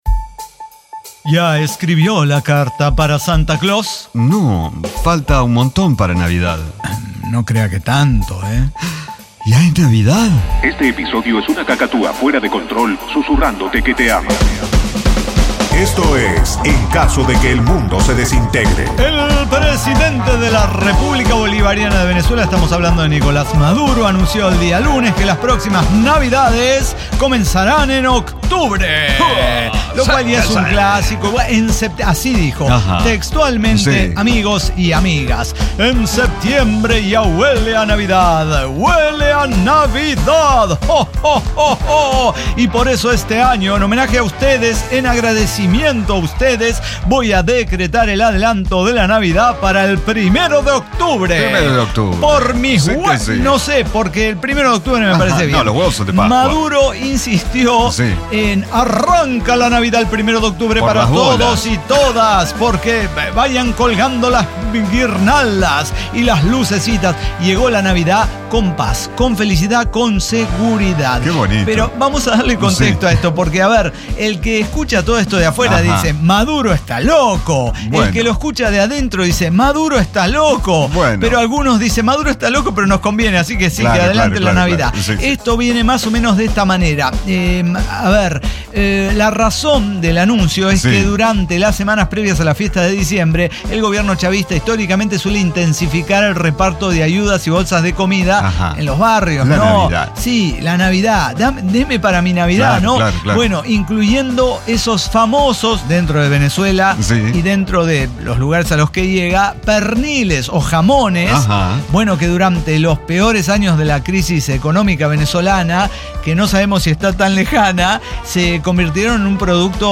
El Cyber Talk Show
Diseño, guionado, música, edición y voces son de nuestra completa intervención humana.